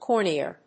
コーニア； コルニエ